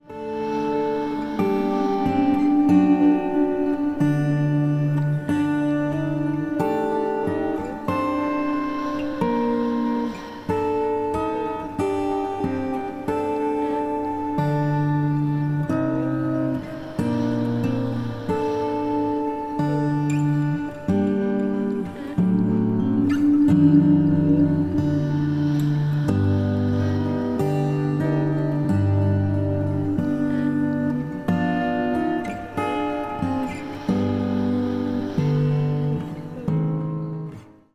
46 bpm
12-string LucyTuned guitar